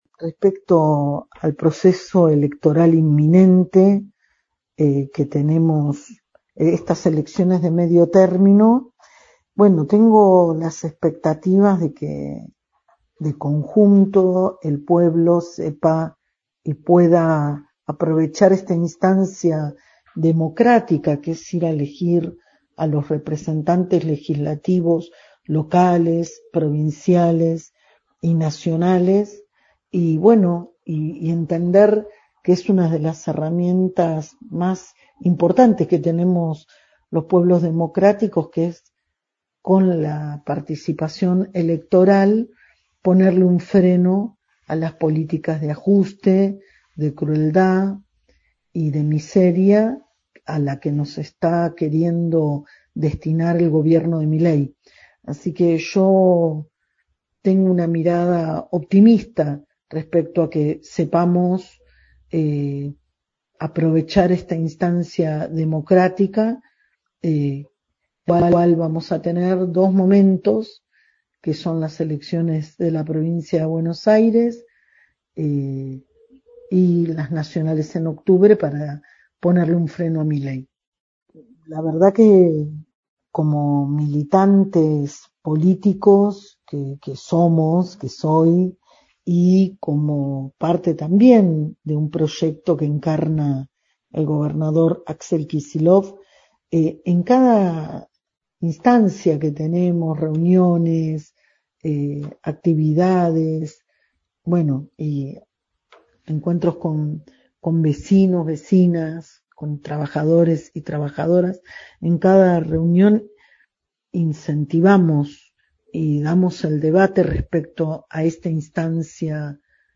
Dialogamos con la compañera Cecilia Cecchini, docente, dirigenta sindical, jefa de Gabinete del Ministerio de Trabajo en PBA, concejala y presidenta del bloque de UxP de Almirante Brown, integrante de la Mesa Provincial de la CTA-T y del Movimiento Mayo y candidata a diputada provincial por la tercera sección electoral de Fuerza Patria.
CECILIA CECCHINI, "FUERZA PATRIA" - ENTREVISTA